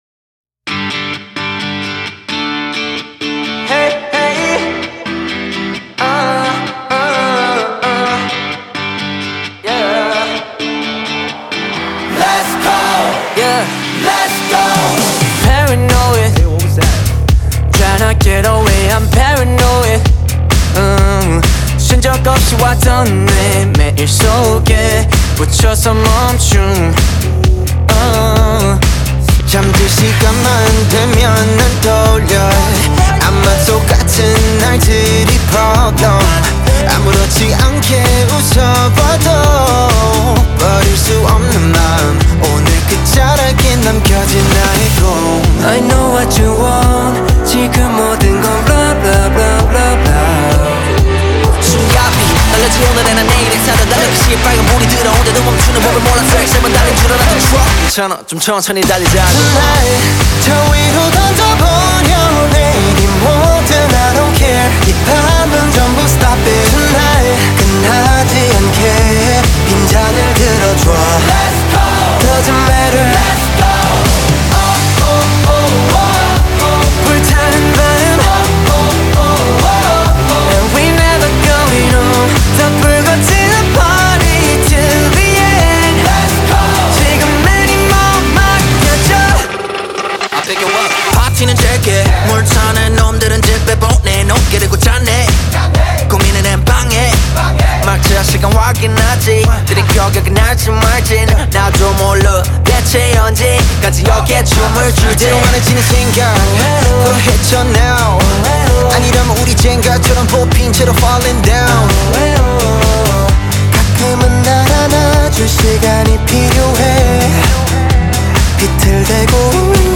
KPop Song